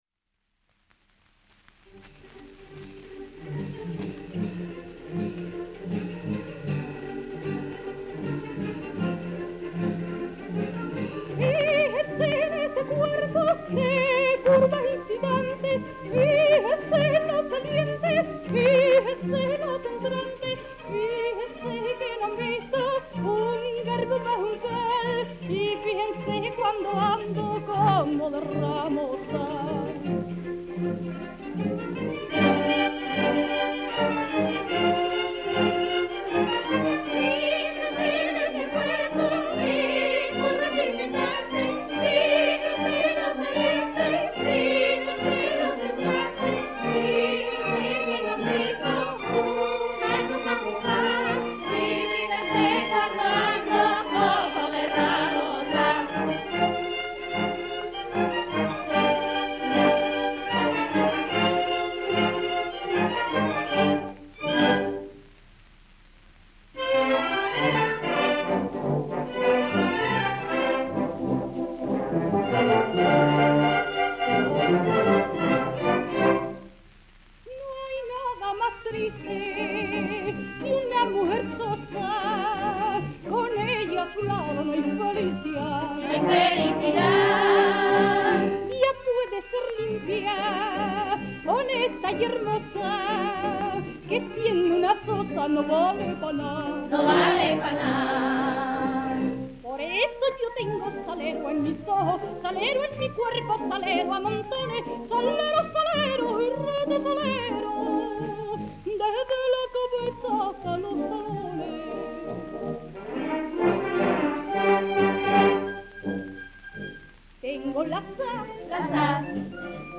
tango
78 rpm